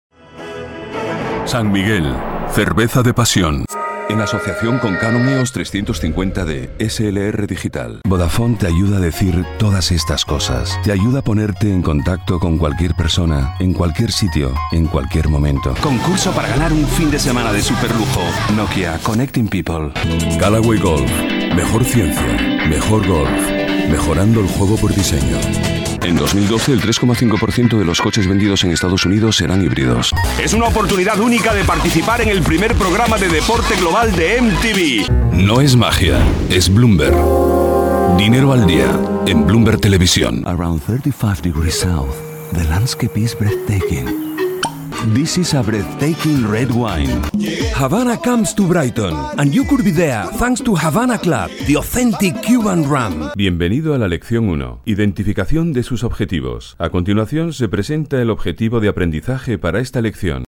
Sprechprobe: Industrie (Muttersprache):
European Spanish male voice-over, huge experience in corporate DVDs, e-learning and audiobooks.